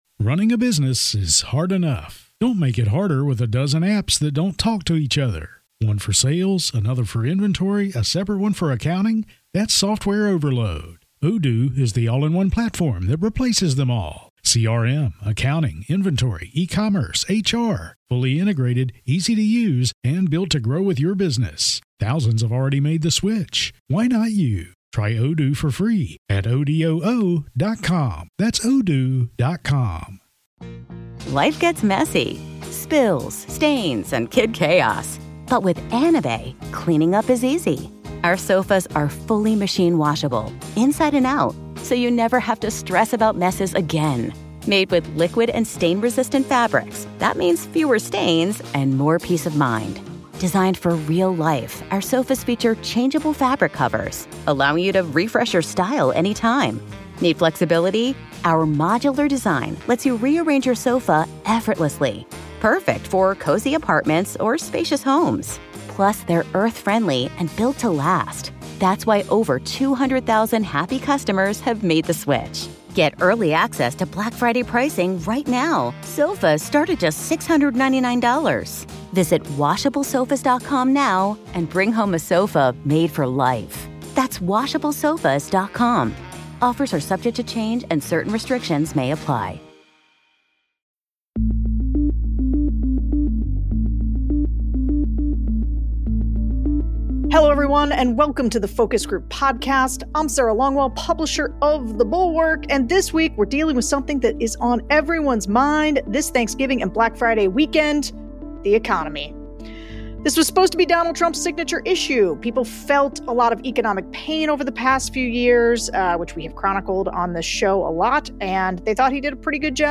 New Bulwark economics editor Catherine Rampell joins the show to discuss the state of the economy and what politicians can and cannot do to fix it. We also hear from voters about how their holiday spending habits have changed since last year.